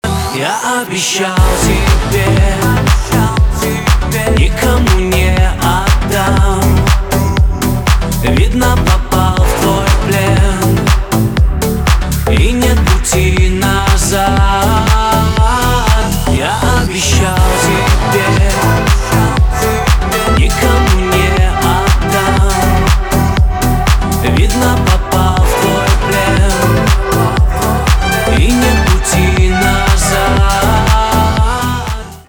поп
битовые , романтические , чувственные , басы